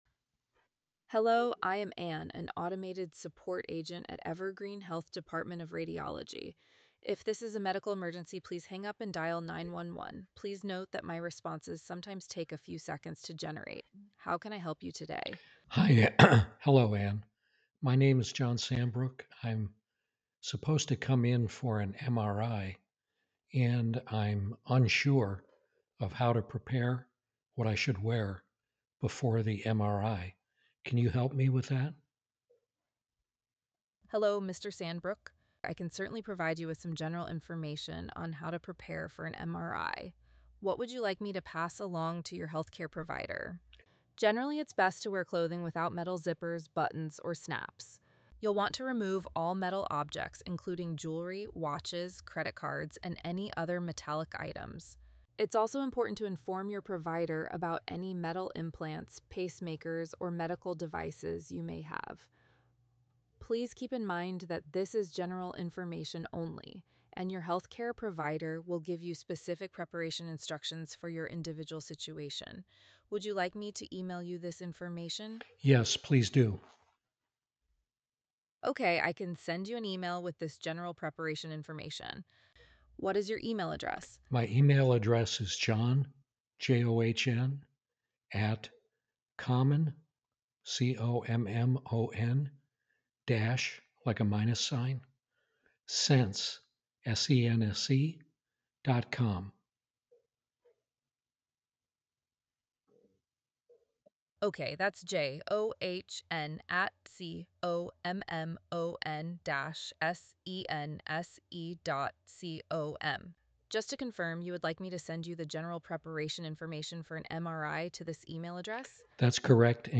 They understand speech and may be multi-lingual, process requests, and respond in human-like conversation.
Hear a Voice Agent in Action
Listen to how natural and helpful voice agents can be in real healthcare interactions:
voice-agent-demo.mp3